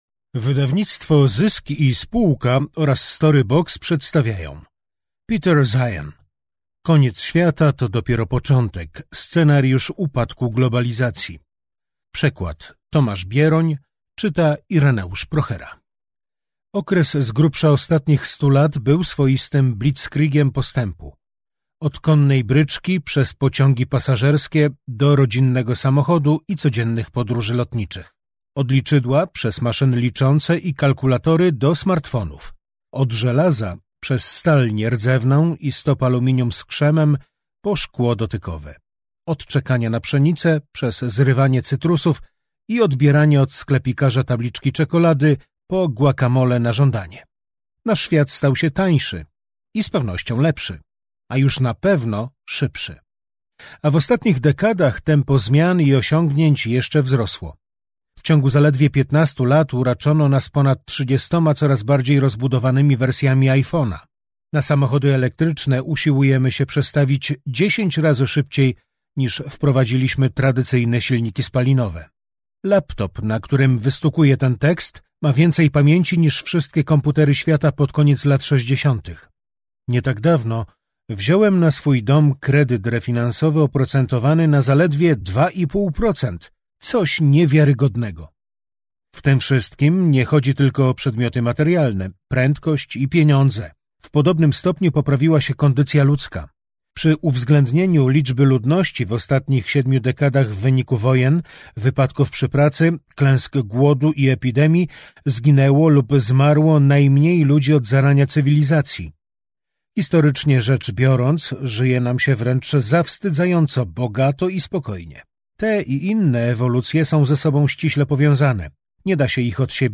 Koniec świata to dopiero początek. Scenariusz upadku globalizacji - Peter Zeihan - audiobook